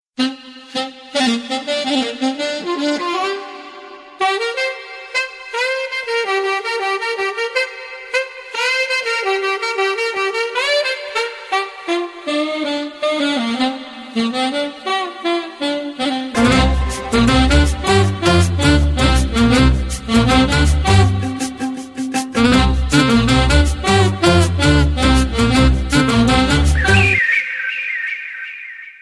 Ringtones Category: Instrumental